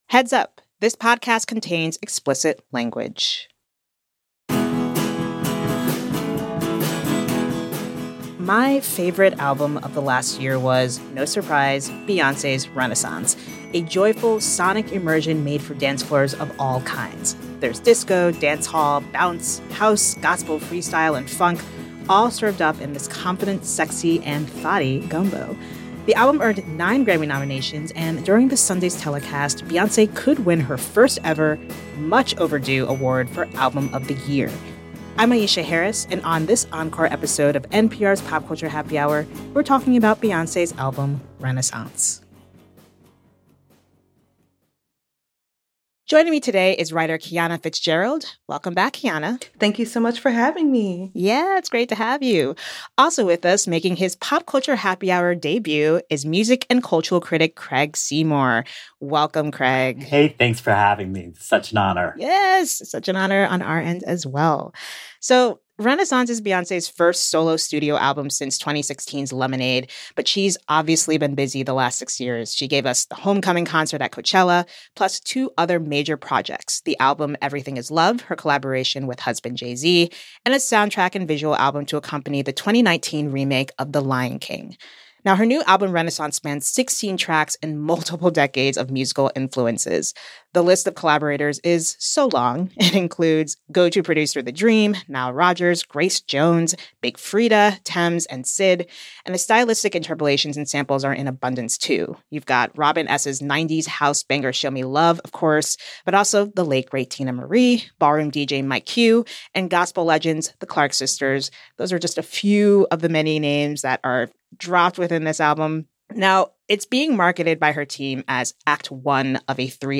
Music Review